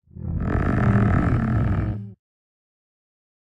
Minecraft Version Minecraft Version snapshot Latest Release | Latest Snapshot snapshot / assets / minecraft / sounds / mob / warden / agitated_4.ogg Compare With Compare With Latest Release | Latest Snapshot
agitated_4.ogg